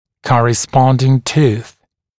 [ˌkɔrɪ’spɔndɪŋ tuːθ][ˌкори’спондин ту:с]соответствующий зуб (в противоположной зубной дуге)